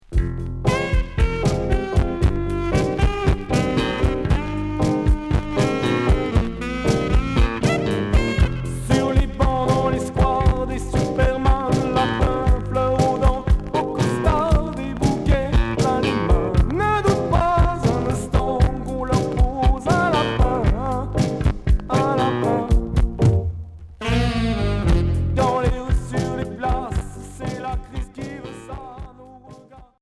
Rock à cuivres Unique 45t retour à l'accueil